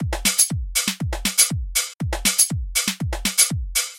鼓楼
描述：简单的循环鼓
标签： 120 bpm Deep House Loops Drum Loops 1.35 MB wav Key : Unknown
声道立体声